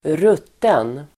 Uttal: [²r'ut:en]